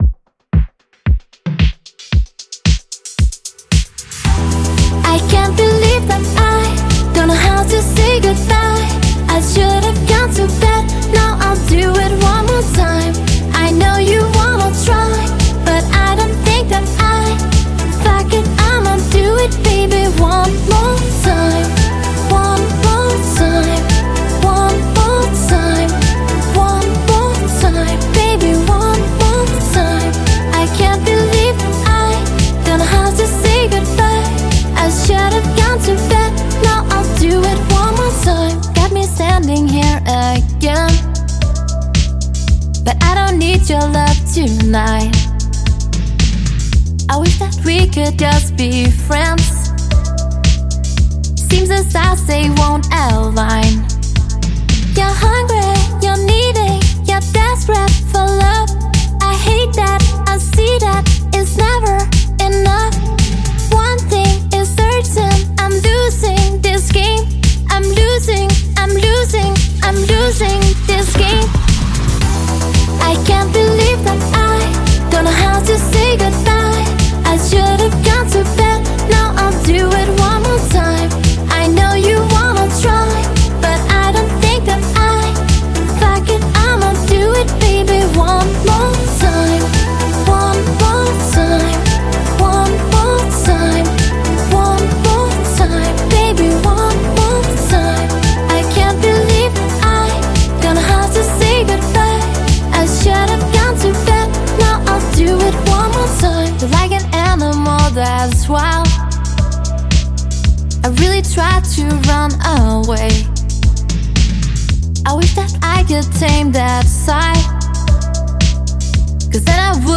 • Pop
Solo
captivating English-language pop songs with electro vibes